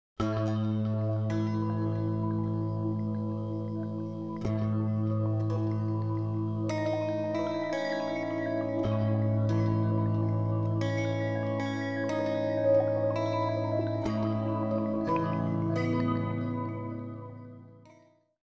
I decided to make three banks of five notes each, double strings for each note.
humbucking pickups from the Stewart-MacDonald guitar supply company.
I wired simply all the pickups together, plugged into my Korg Pandora signal processing box, and recorded a little song.